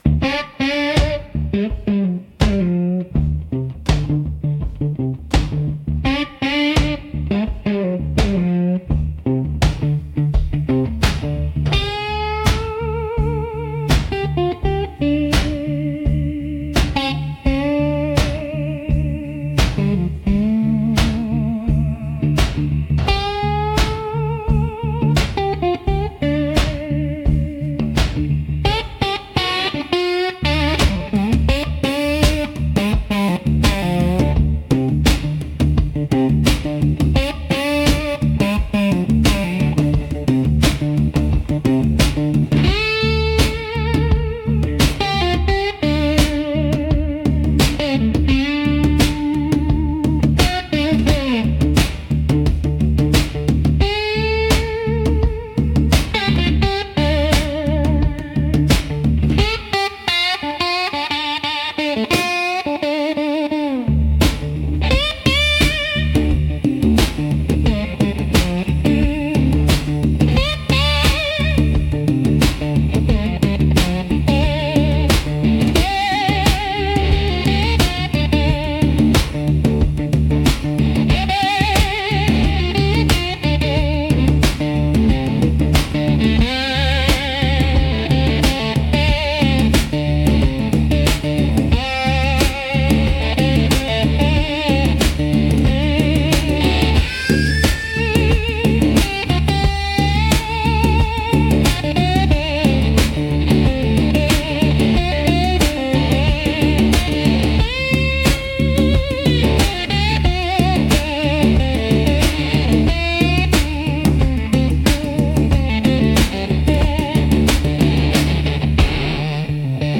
Instrumental - Chain-Gang Groove